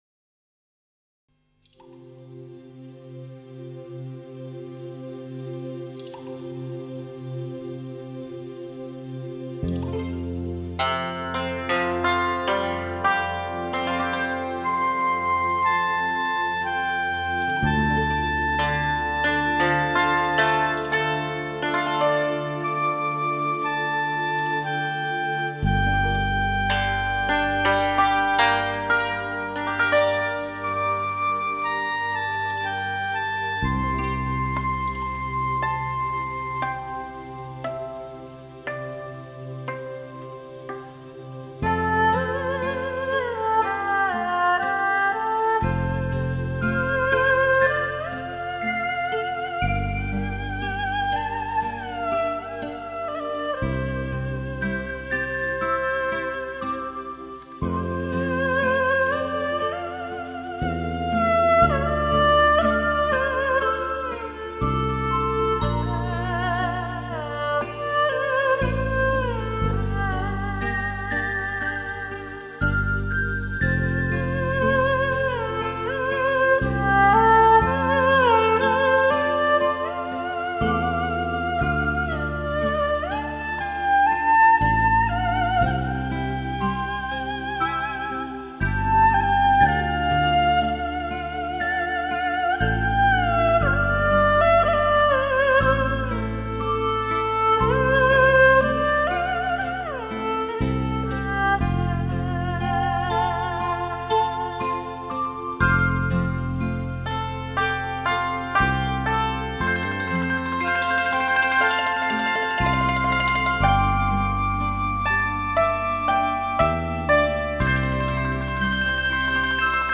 音质很棒。带着中国民族音乐浓郁风味的经典新世纪休闲音乐，绝对的超值珍藏品。
旋律清幽抒情